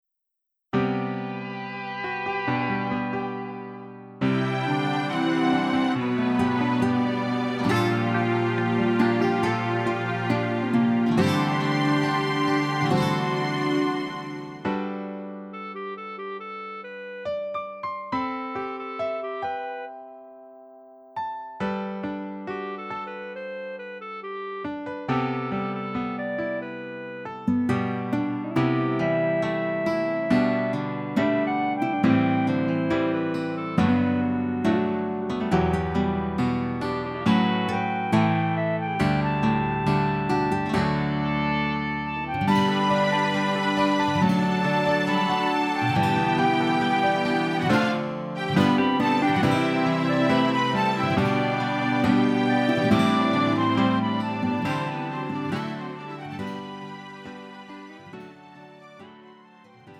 음정 -1키 3:29
장르 가요 구분 Lite MR
Lite MR은 저렴한 가격에 간단한 연습이나 취미용으로 활용할 수 있는 가벼운 반주입니다.